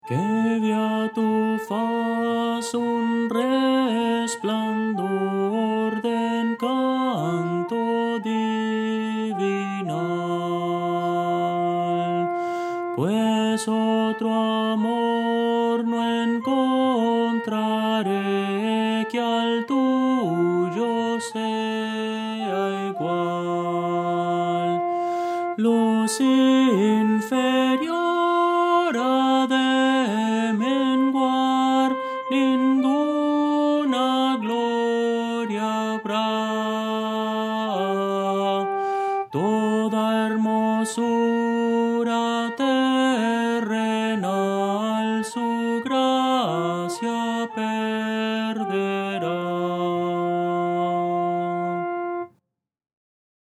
Voces para coro
Soprano – Descargar
Audio: MIDI